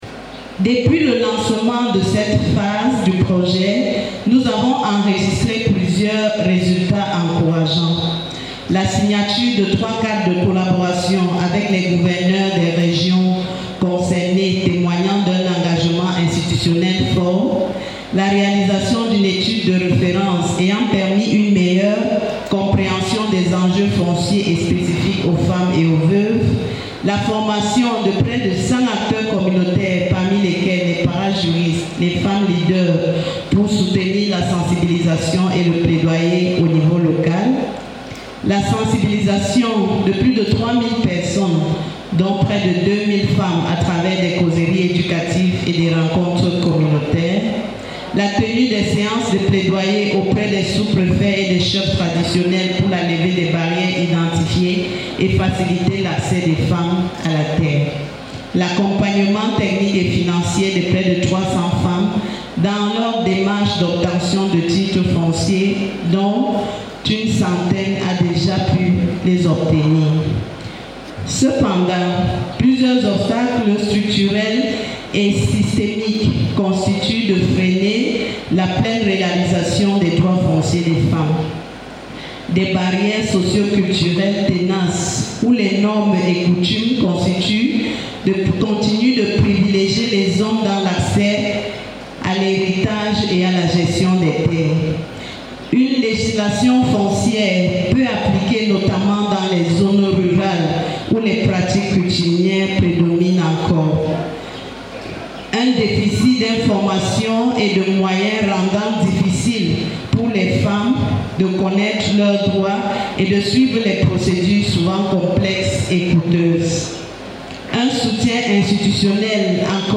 Dans son discours, il a été également question de mettre en vitrine les progrès significatifs de ce projet.